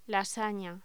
Locución: Lasaña